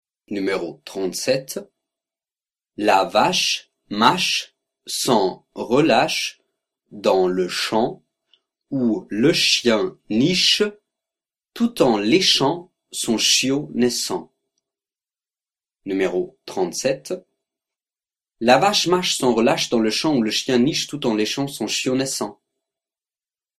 37 Virelangue